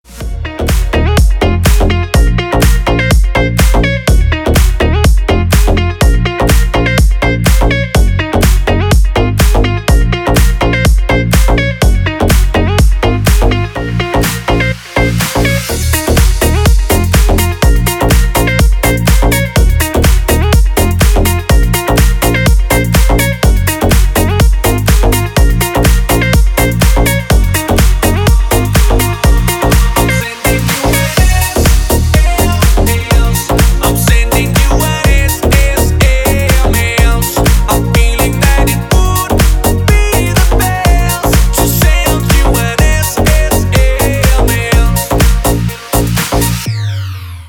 играет Dance рингтоны🎙